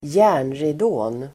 Ladda ner uttalet
Uttal: [²j'ä:r_nridå:n]